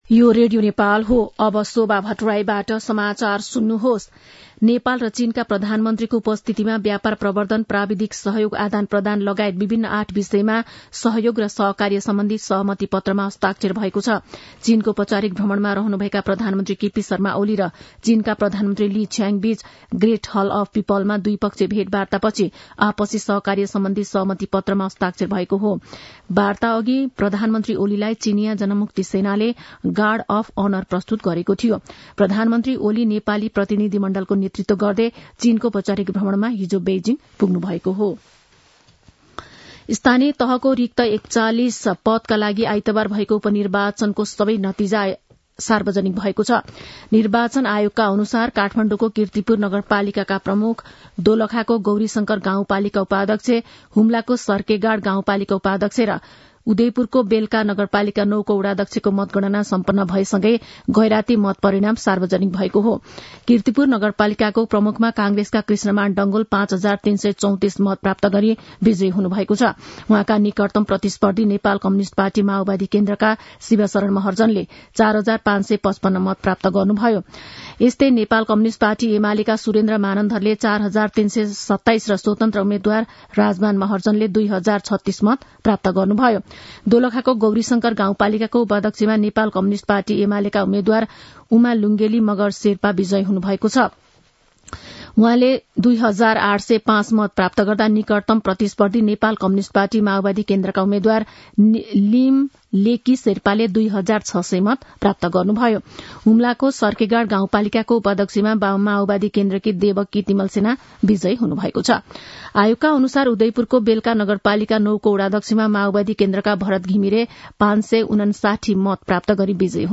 12-am-nepali-news-1-1.mp3